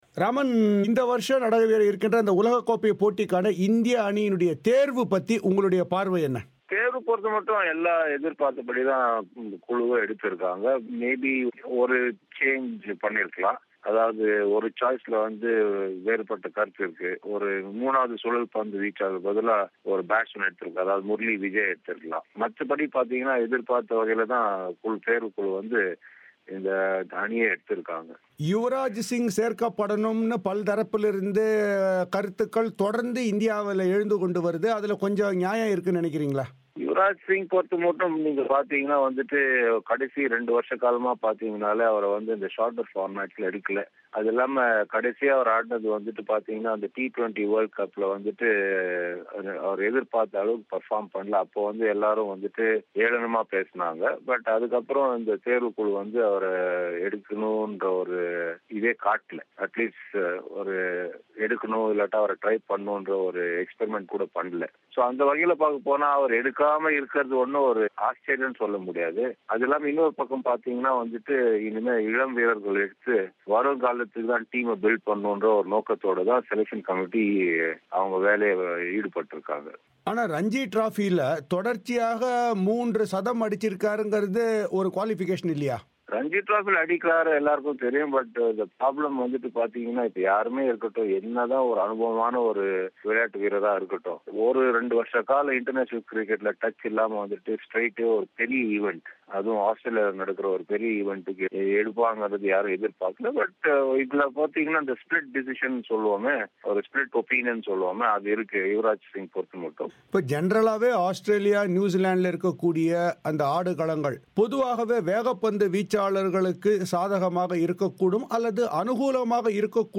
இப்போட்டிக்கு தேர்தெடுக்கப்பட்டுள்ள அணி, ஆஸ்திரேலிய ஆடுகளங்கள் மற்றும் இந்தியாவின் வெற்றி வாய்ப்பு ஆகியவை குறித்து இந்திய அணியின் முன்னாள் வீரர் W V ராமன் பிபிசி தமிழோசைக்கு வழங்கிய பேட்டியை இங்கே கேட்க்லாம்.